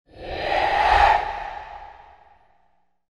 supernatural-breath-sound